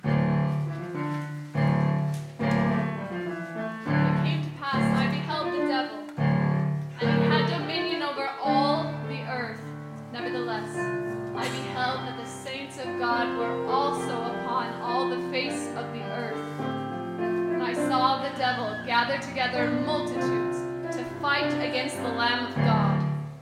Soprano and Piano